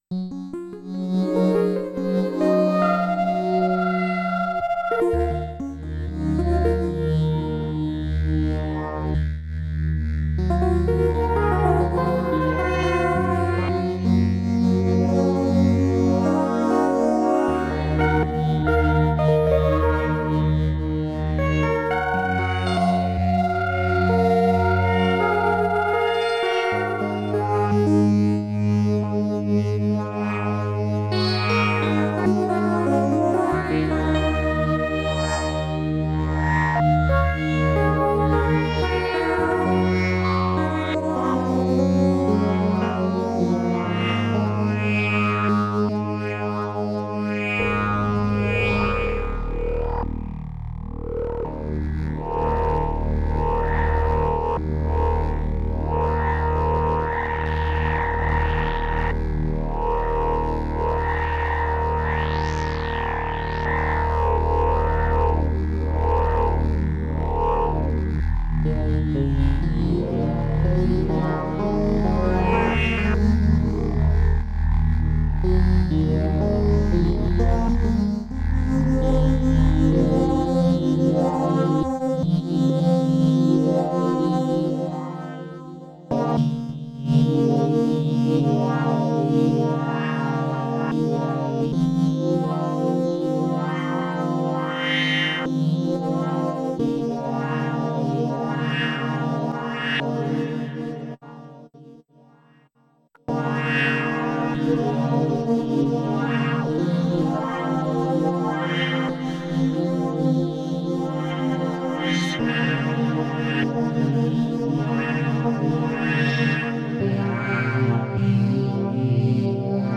I kick on effects like half way through (not that it matters)